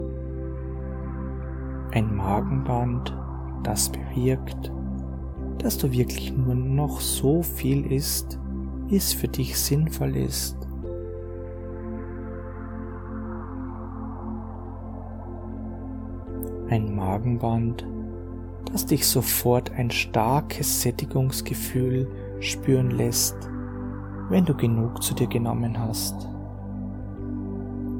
G2003-Erstsitzung-Hypnotisches-Magenband-maennliche-Stimme-Hoerprobe.mp3